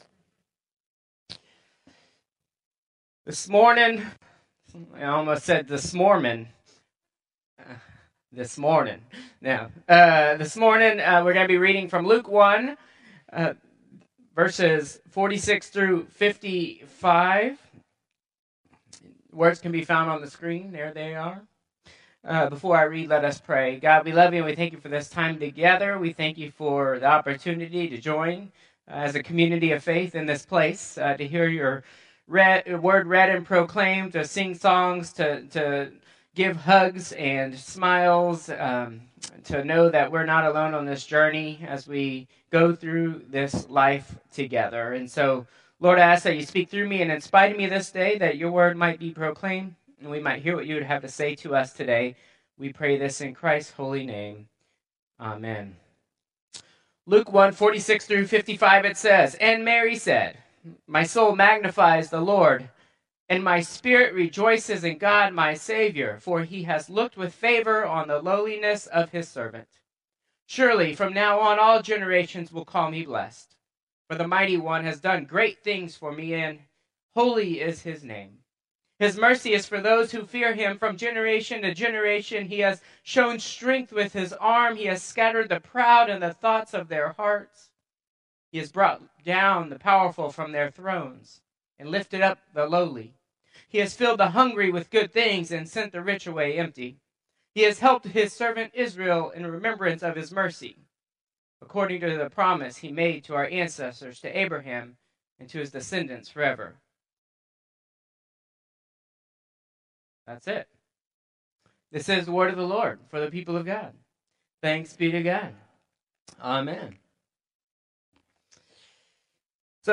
Contemporary Service 12/22/2024